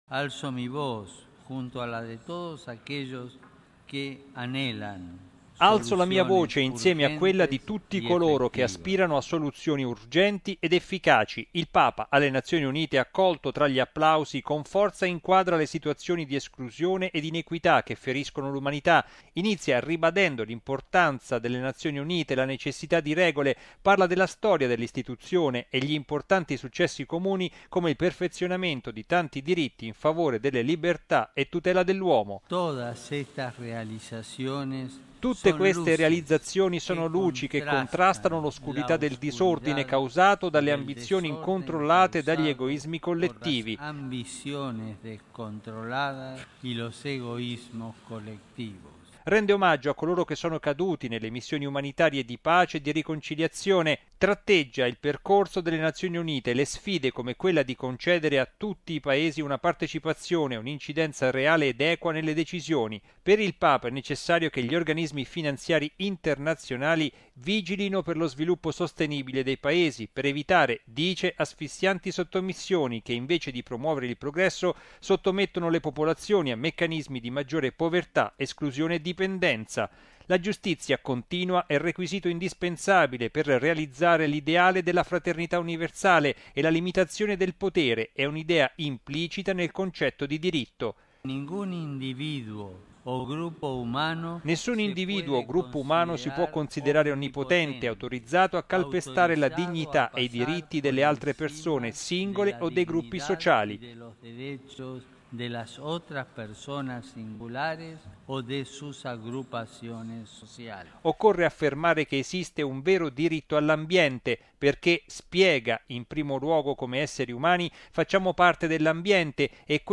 Il Papa alle Nazioni Unite, accolto tra gli applausi, con forza inquadra le situazioni di esclusione e di inequità che feriscono l’umanità.